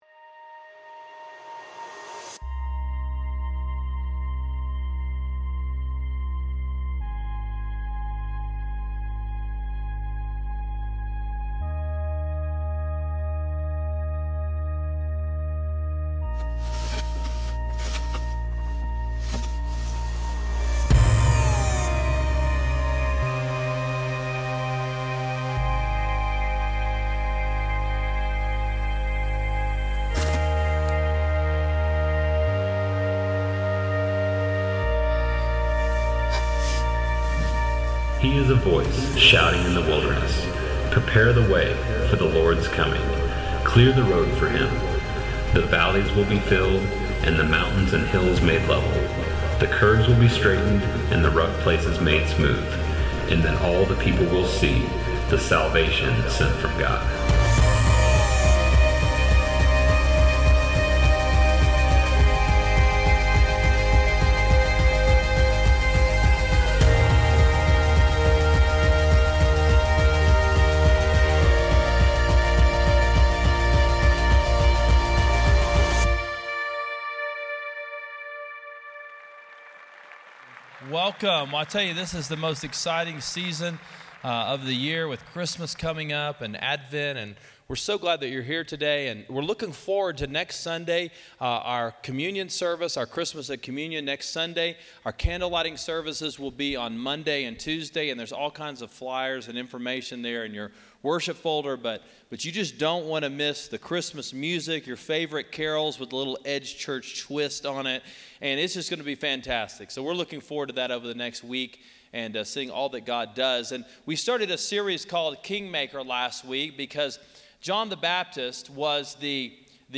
Kingmaker: Selfie-Developing A Life Of Humility: John 3:30 – Sermon Sidekick